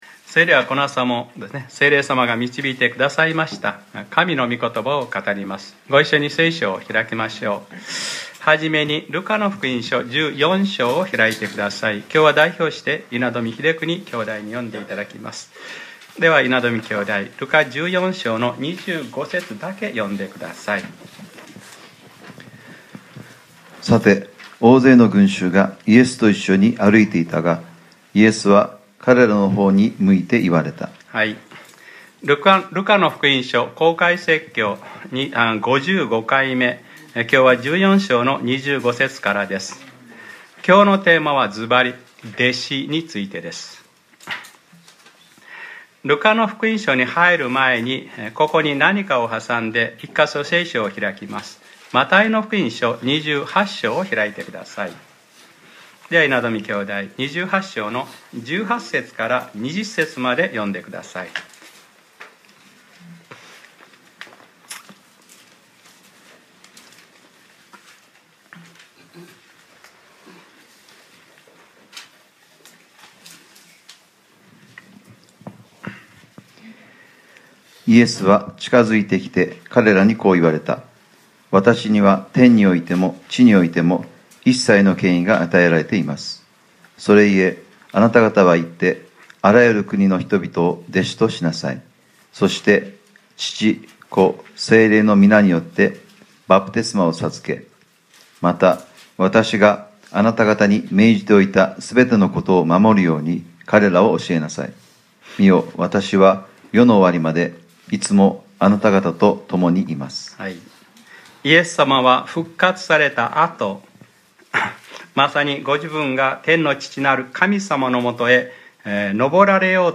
2015年03月01日）礼拝説教 『ルカｰ５５：弟子の３条件』